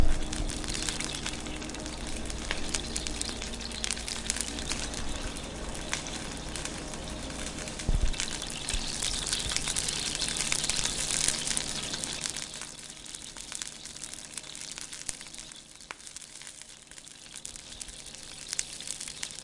煎炸培根编辑
描述：这声音是在平底锅上煎培根。